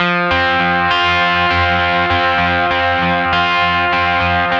5 audio dguitar1s.wav